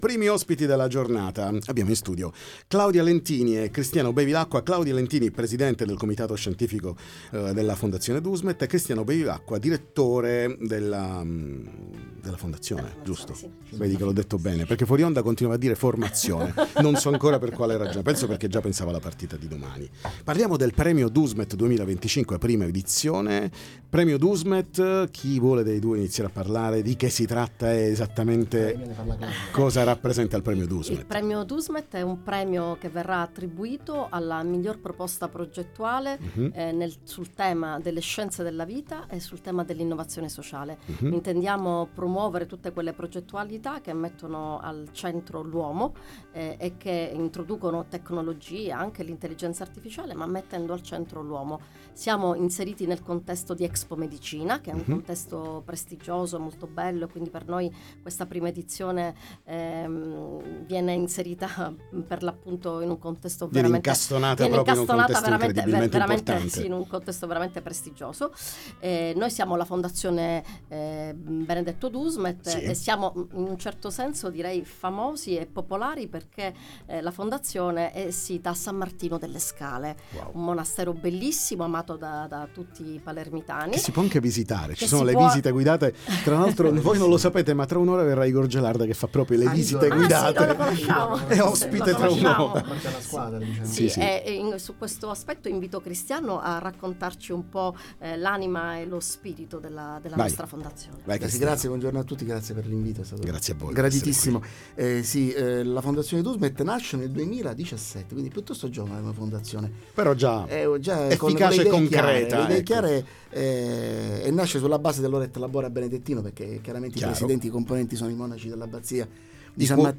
Dusmet News – 29.10.2025 PAD. 20 – Fiera del Mediterraneo Interviste Time Magazine 24/10/2025 12:00:00 AM / Time Magazine Condividi: Dusmet News – 29.10.2025 PAD. 20 – Fiera del Mediterraneo